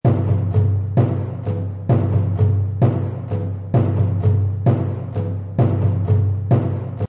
War Drum Loop 103870 Mp 3